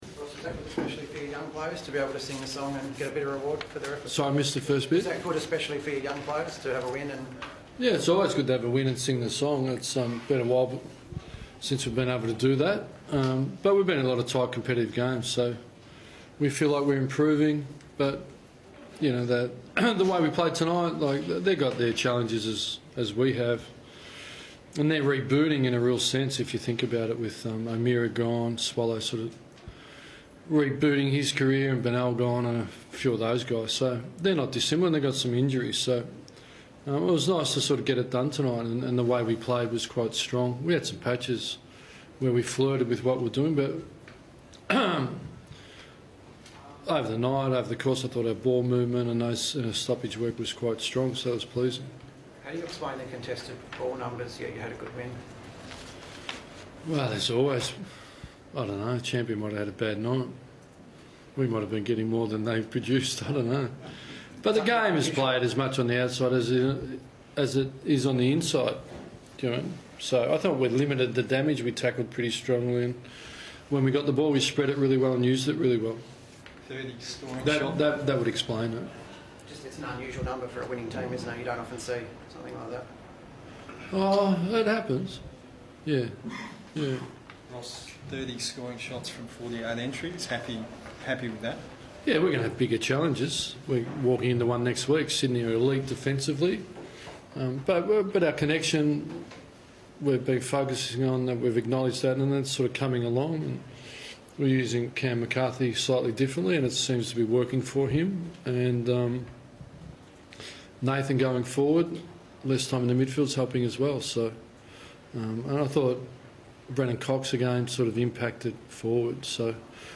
Ross Lyon chats to the media after Freo's clash against the Suns.